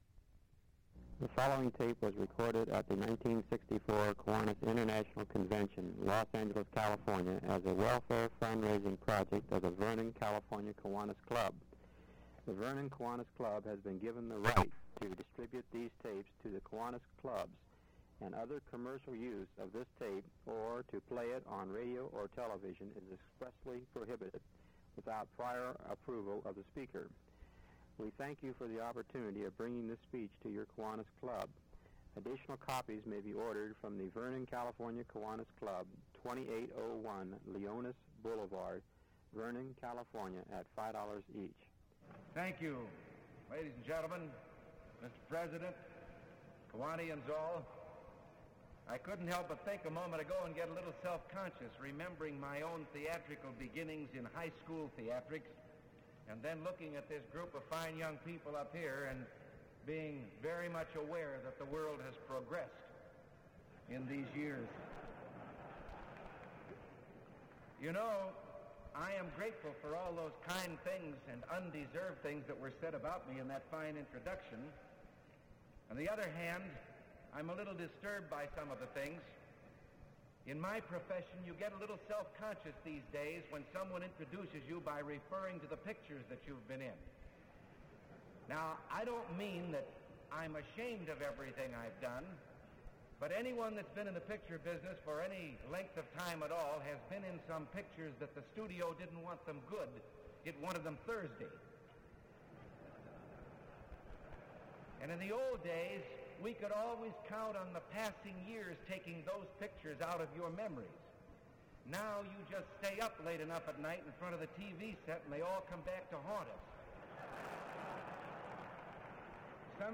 “A Time for Choosing” Ronald Reagan Speech at Kiwanis International Convention, Los Angeles, California